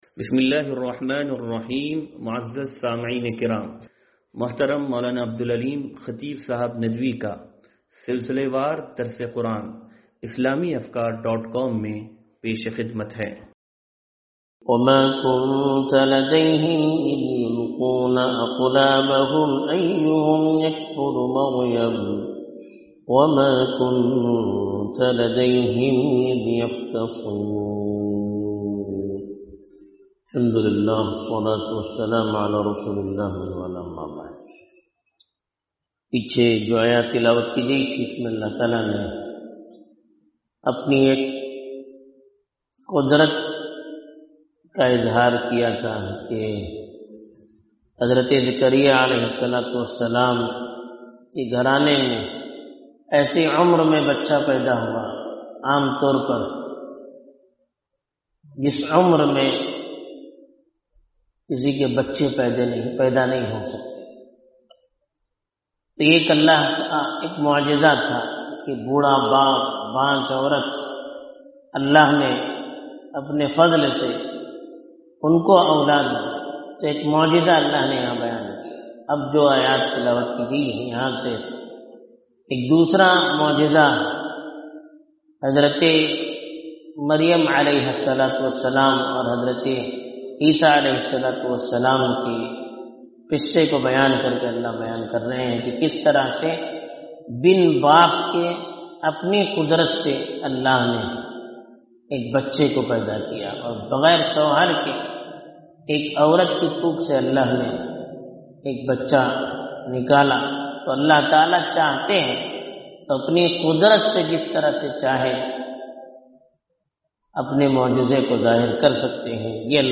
درس قرآن نمبر 0241